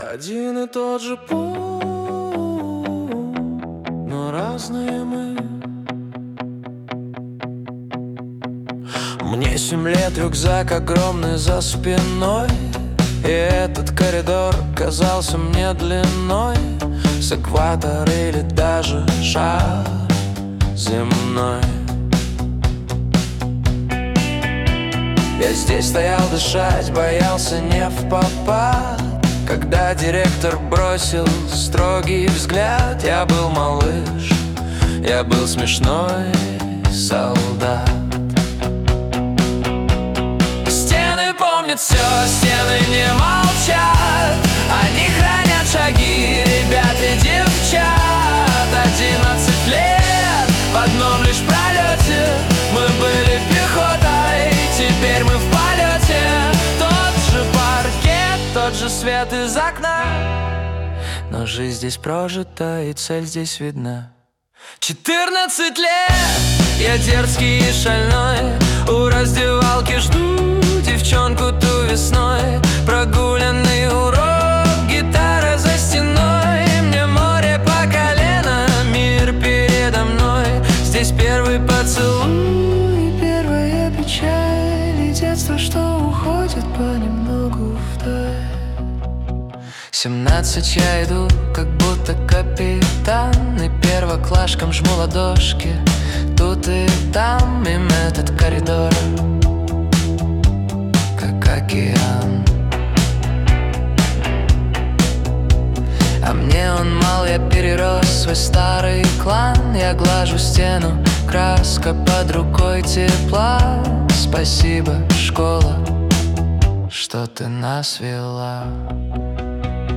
ностальгический indie-pop стиль
Выбор пал на Indie Pop с элементами ностальгии.